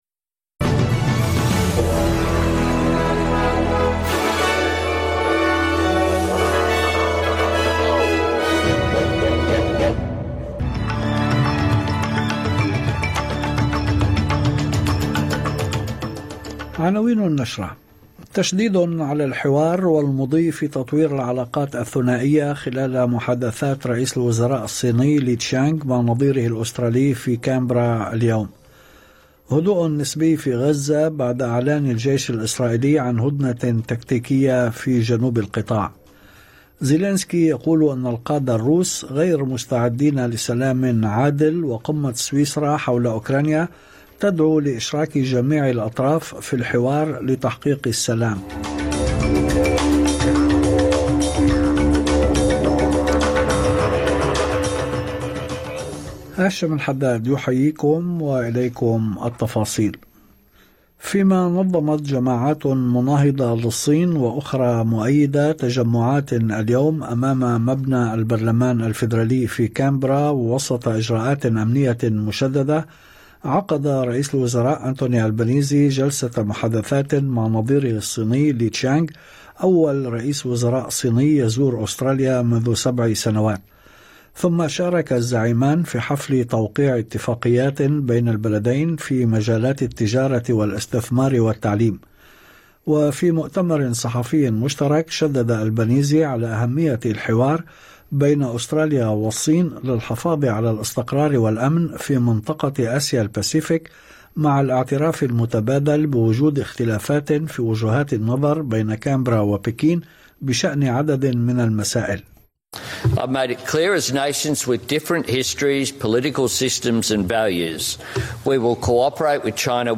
نشرة أخبار المساء 17/06/2024